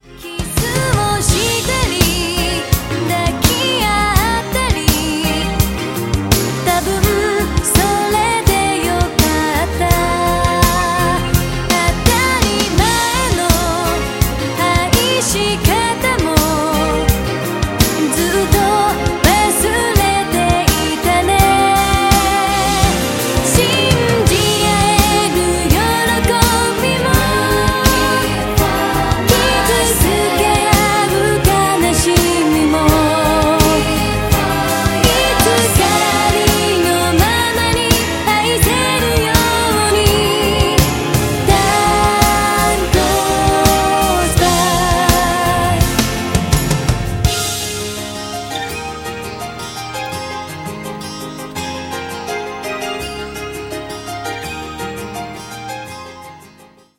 Strings